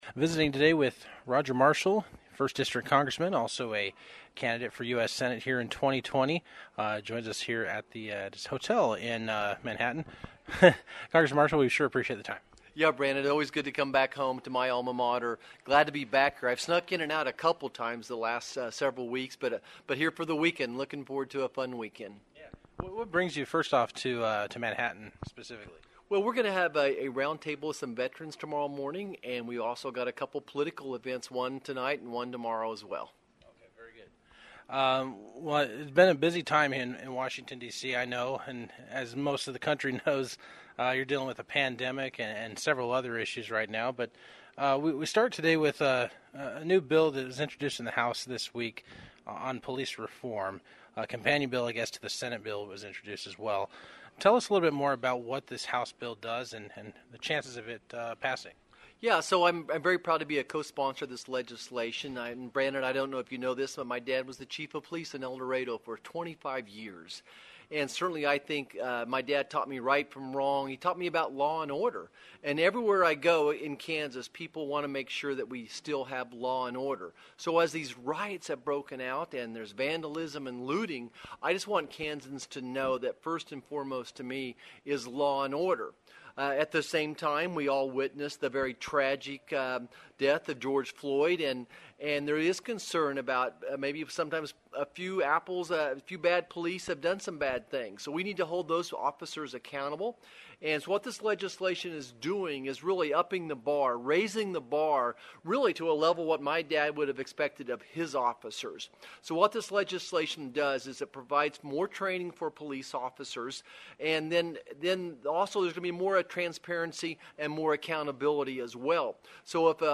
Marshall, who joined KMAN in a Friday interview, says the bill provides more training for police officers, along with more transparency and accountability.